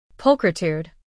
播放发音）表示“美丽”，不过它不像常见的“beautiful”那么直白，更偏向于形容那种令人惊艳、优雅的美。
pulchritude-pronunciation.mp3